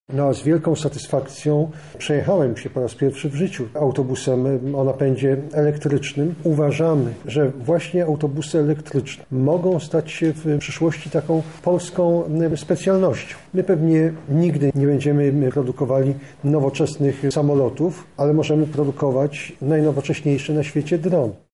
-Musimy wykorzystać potencjał jaki drzemie w naszych projektach – mówi minister Jarosław Gowin